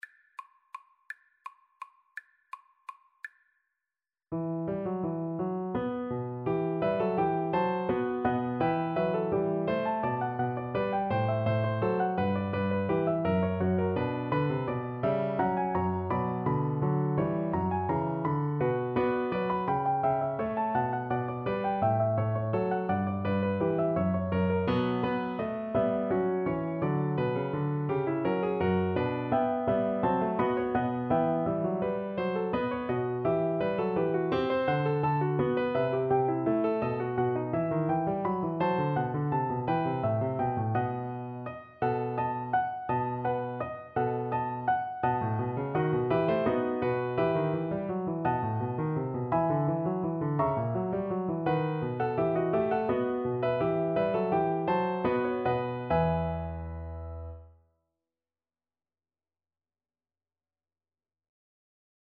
3/8 (View more 3/8 Music)
Classical (View more Classical Viola Music)